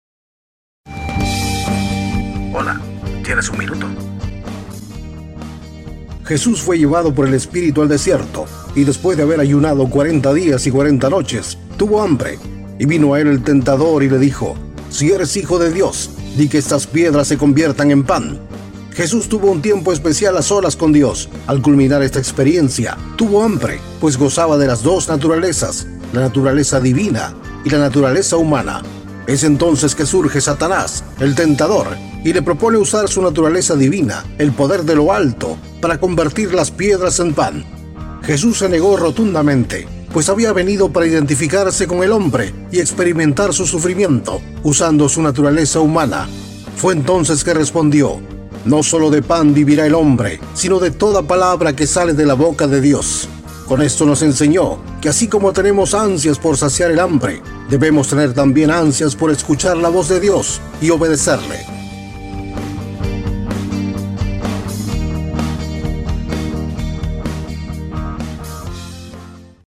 Programas radiales
Programa radial de 60 segundos, donde abordamos la vida de Jesús y sus palabras, aplicadas al mundo moderno.